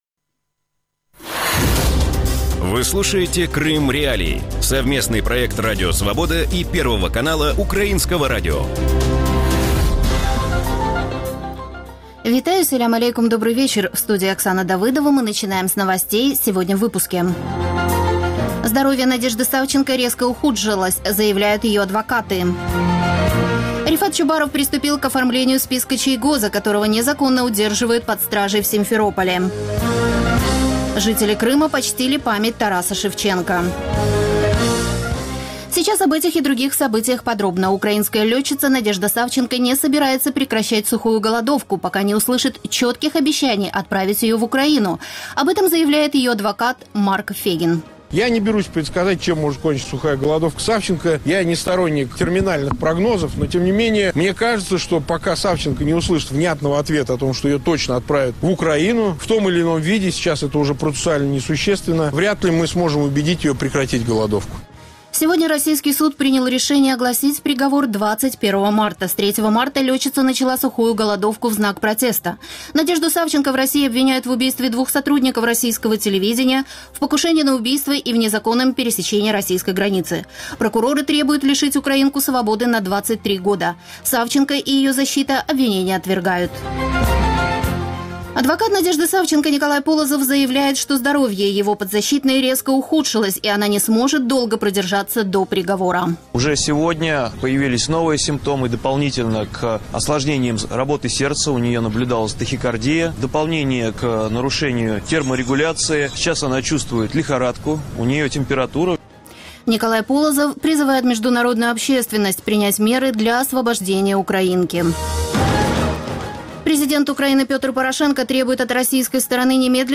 Новости Радио Крым.Реалии